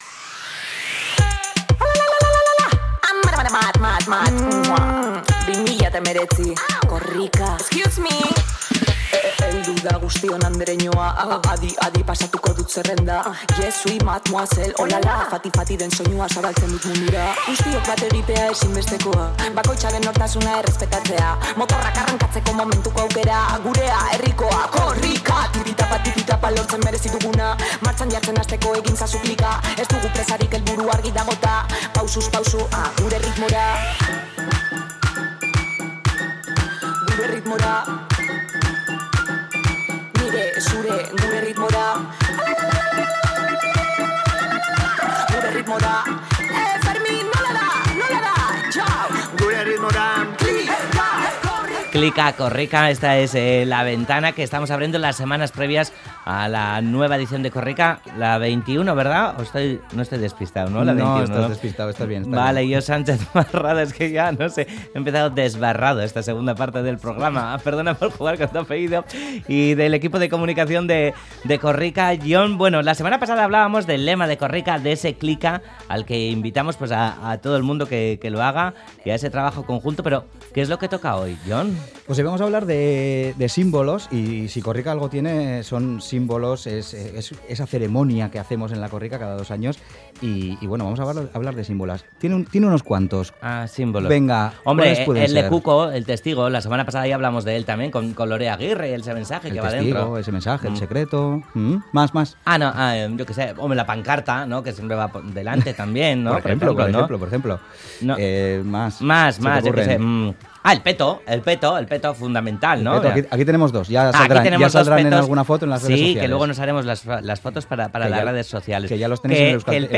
Audio: Esta semana en la sección Klika Graffitera un veterano furgonetero de Korrika y una a punto de estrenarse nos dan detalles de cómo ven la carrera.